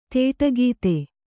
♪ tēṭa gīte